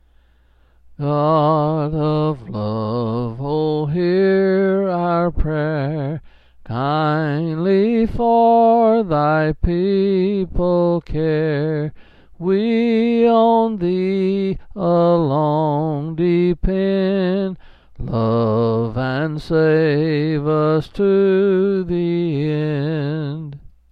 Quill Pin Selected Hymn
7s.